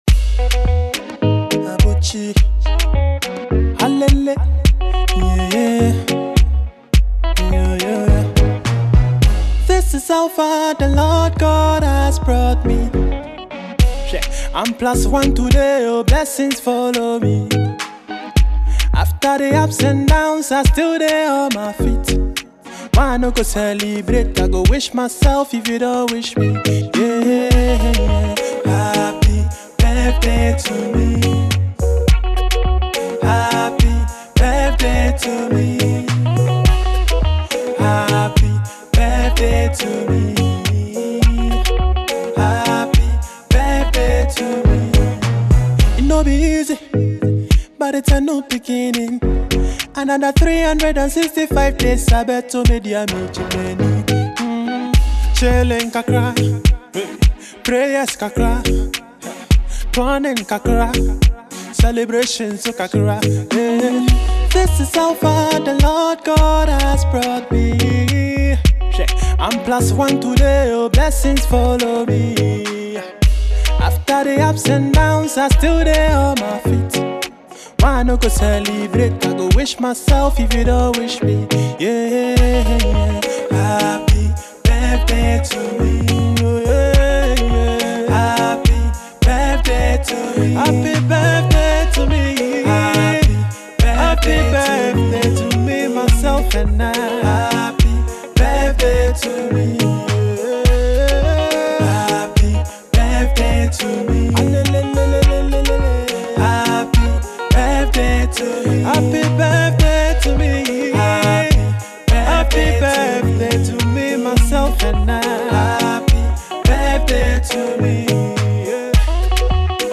Genre: Highlife